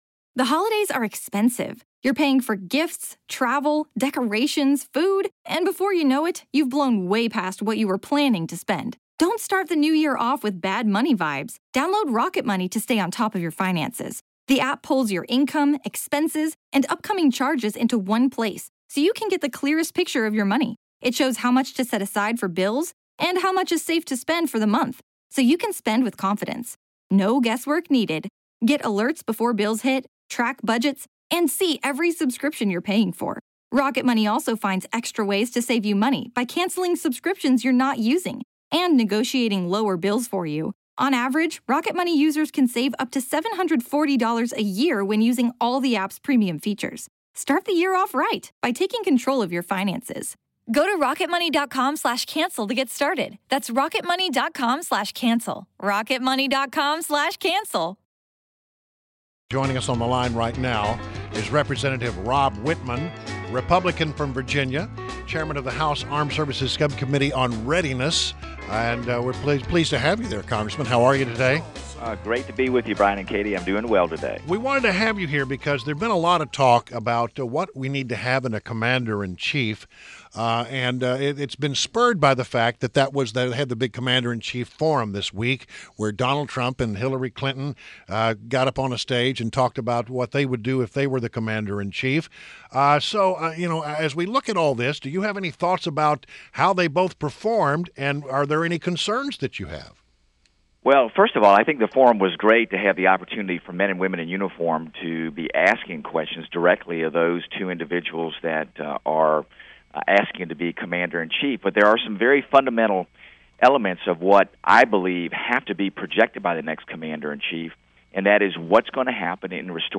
WMAL Interview - REP. ROB WITTMAN - 09.09.16